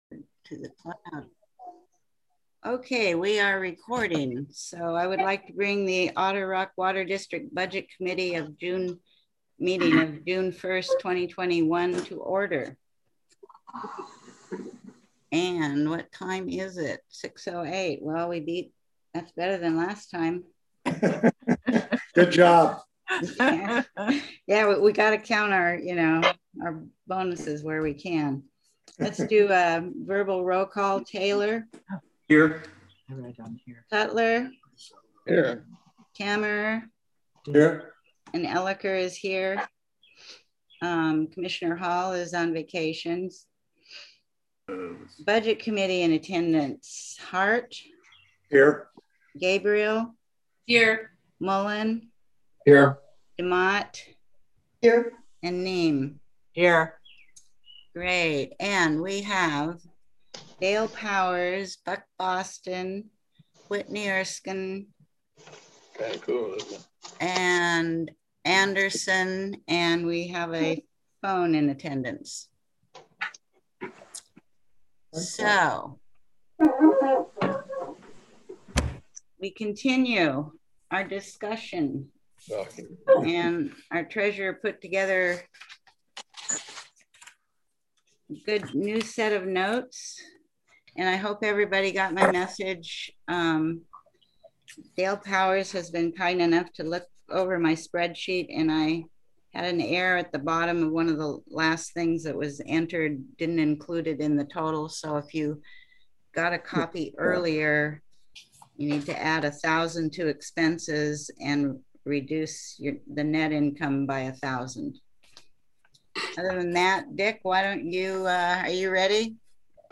All district meetings are public meetings and interested persons are invited to attend.
Budget Committee Meeting